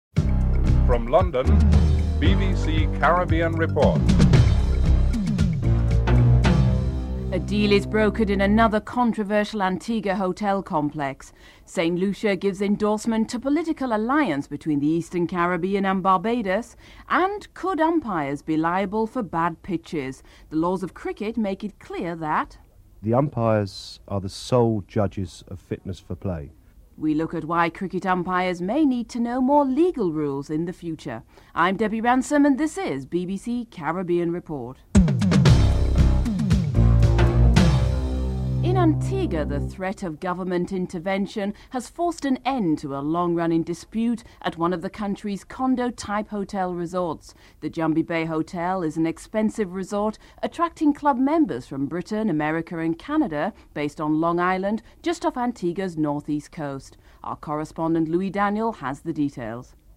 St. Lucia's Prime Minister Kenny Anthony gives his view on a possible union between the Eastern Caribbean and Barbados.